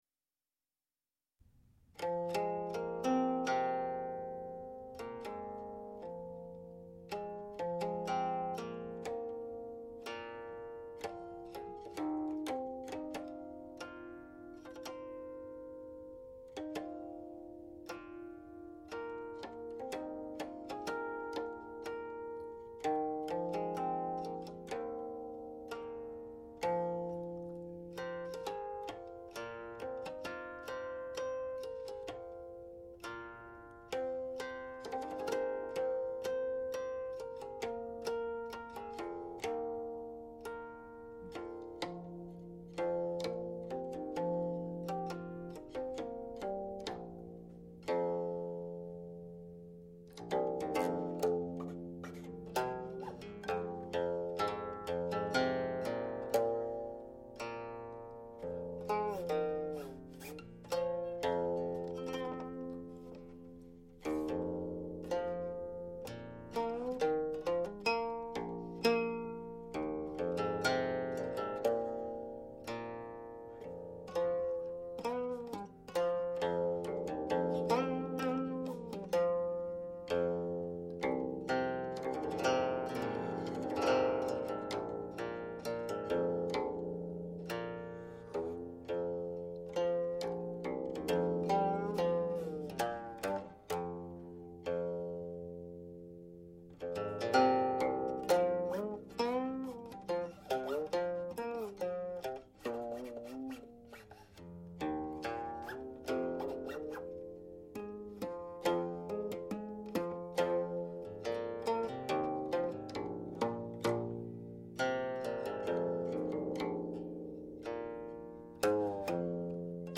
1. Guqin Blues (or: Silkqin Blues 2)
Raised fifth string tuning; further above.